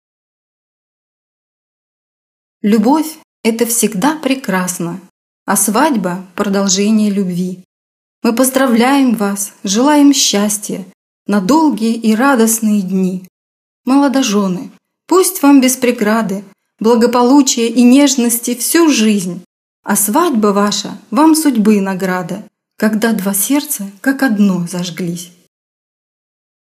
Rednerin für russische Trauungen, Co-Rednerin bei zweisprachigen Trauungen, Rednerin bei leicht orthodoxen Trauungen
Meine Muttersprache ist russisch.